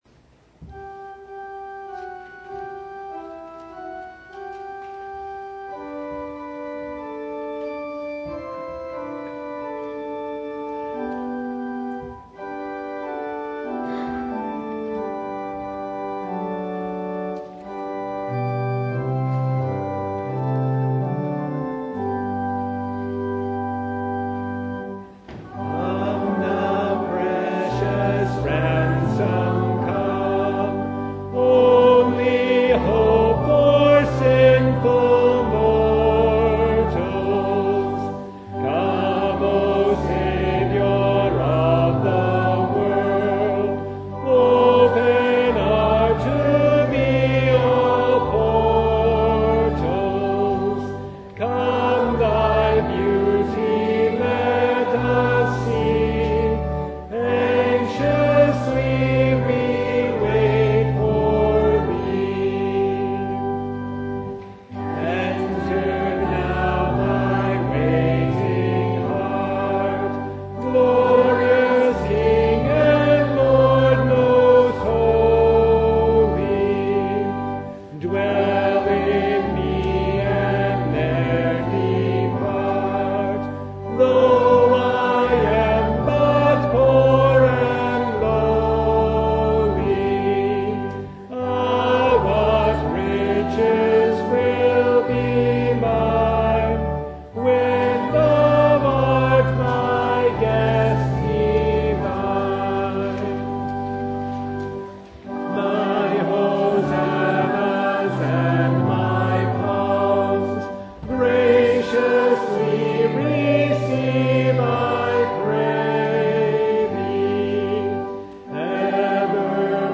Service Type: Advent Vespers
Topics: Full Service